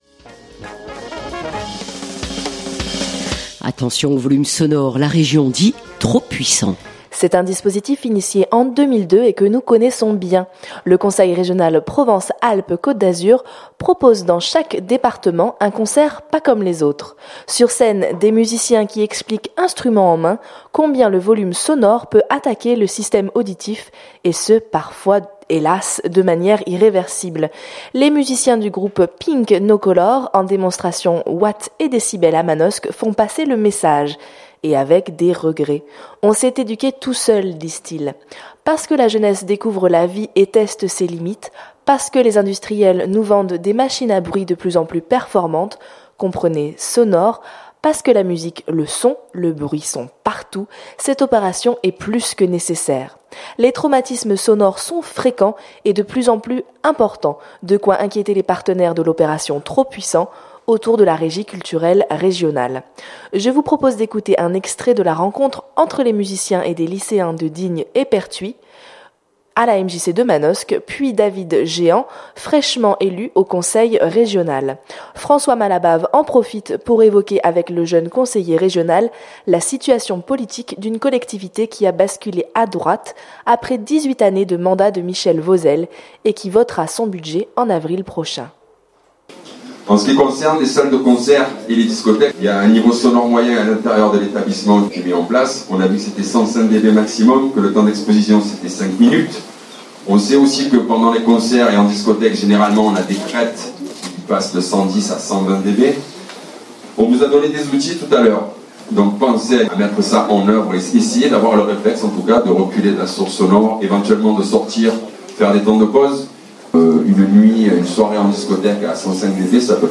Je vous propose d’écouter un extrait de la rencontre entre les musiciens et des lycéens de Digne et Pertuis à la MJC de Manosque puis David Géhant, fraîchement élu au Conseil régional.